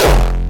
阿特摩斯硬式踢球
描述：带有气氛效果的硬式踢球。
标签： 130 bpm Hardstyle Loops Drum Loops 86.27 KB wav Key : Unknown FL Studio
声道立体声